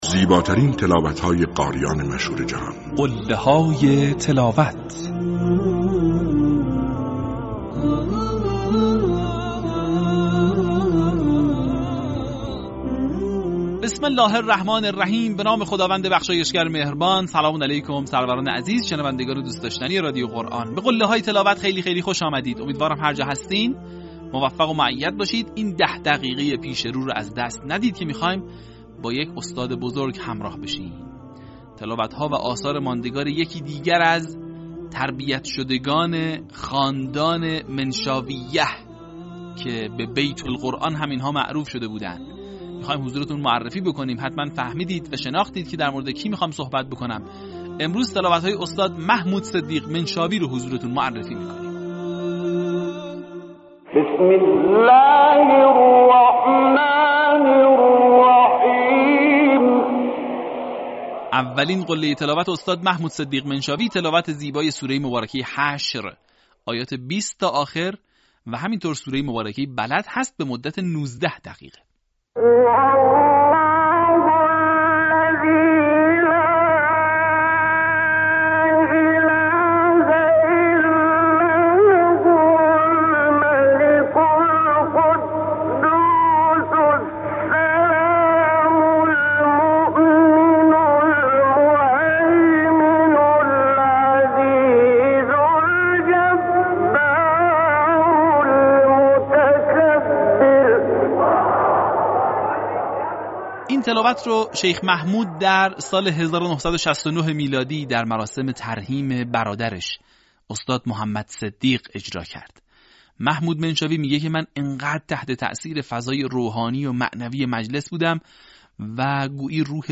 در قسمت پنجاه‌وسوم فراز‌های شنیدنی از تلاوت‌های به‌یاد ماندنی استاد «محمود صدیق منشاوی» را می‌شنوید.
برچسب ها: محمود صدیق منشاوی ، قله های تلاوت ، فراز تقلیدی ، تلاوت ماندگار